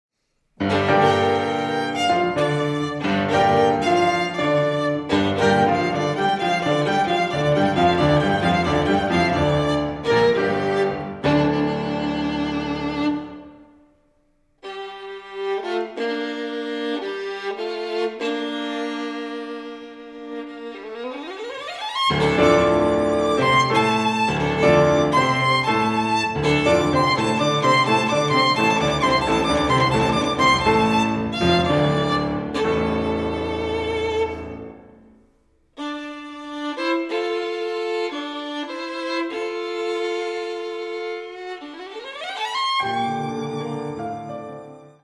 Allegro molto (6:47)
in 1917 in a late romantic style